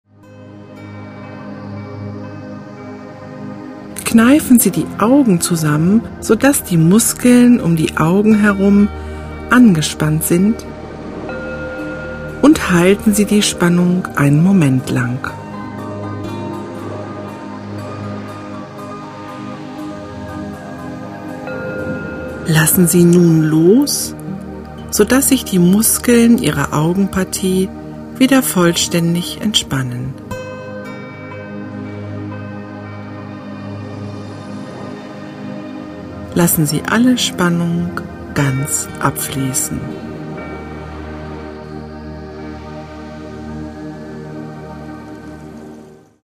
Musik: N.N.